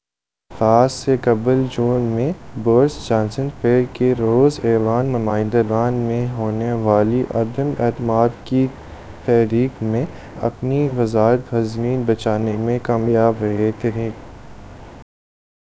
Spoofed_TTS/Speaker_09/260.wav · CSALT/deepfake_detection_dataset_urdu at main
deepfake_detection_dataset_urdu / Spoofed_TTS /Speaker_09 /260.wav